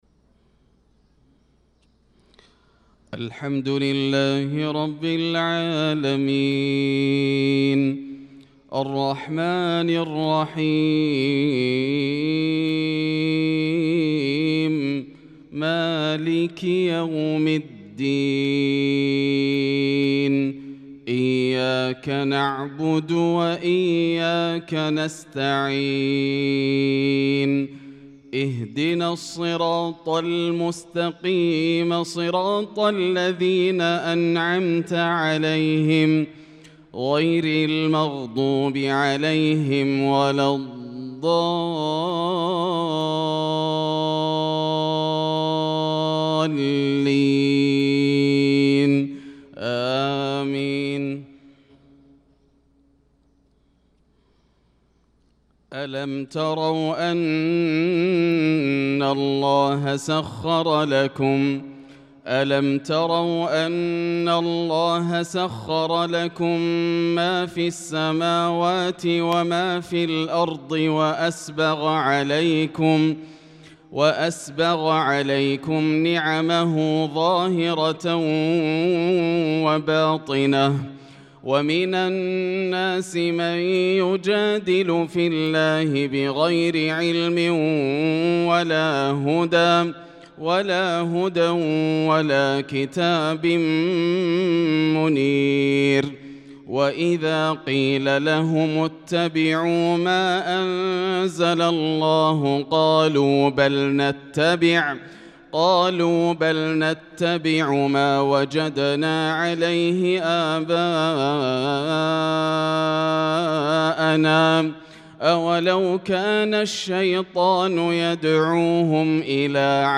صلاة الفجر للقارئ ياسر الدوسري 9 شوال 1445 هـ